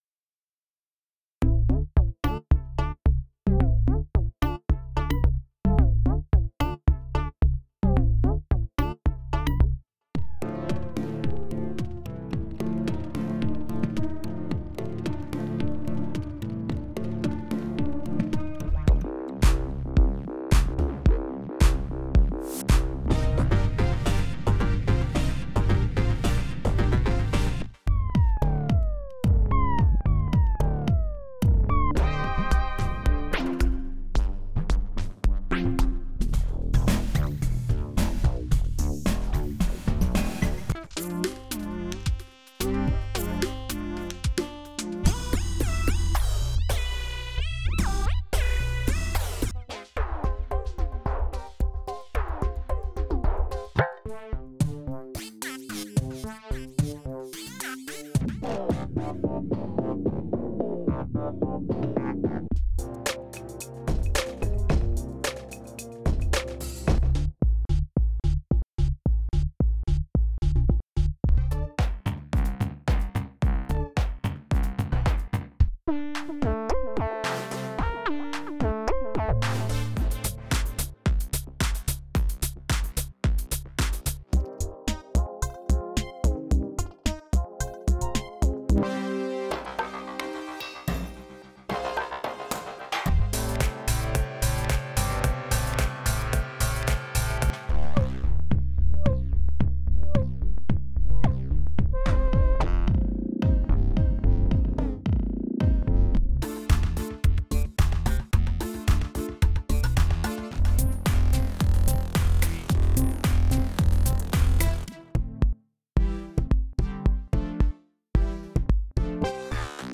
ドラム音源からメロディック・サウンドを生成するREAKTORシンセですが、基本操作はこんな感じでシンプルです。
今までに無かった奇妙なタイプのシンセですが、MIDIを使わずドラム音源からのオーディオ出力だけからリアルタイムに多彩なサウンドが得られそれを変化させることができます。
FLESH左側のプリセットだけを変えて録音した曲？が以下です。
FLESH-Preset-Rec-1.mp3